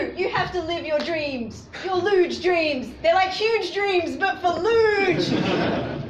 Tags: rap